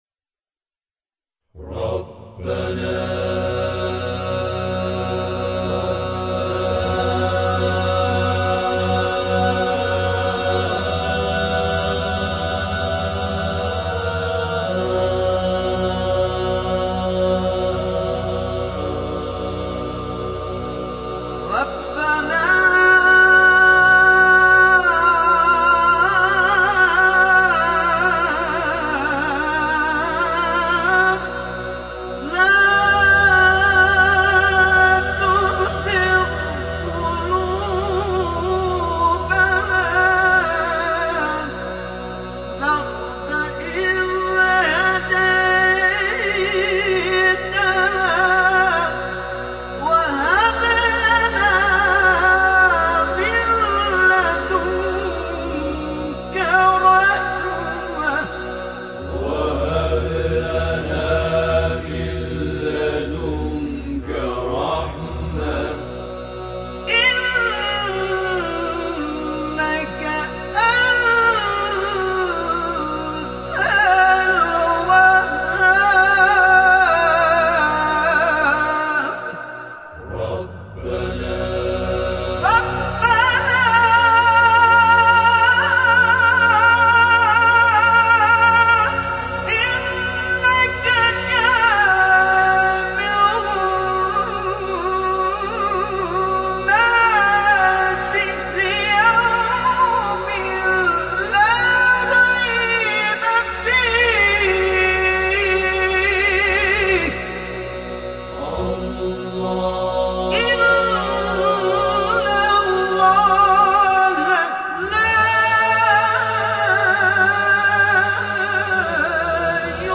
قاری پیشکسوت کشورمان
فرازهای دوم نیز در همین مایه
به همراه گروه هم‌خوانی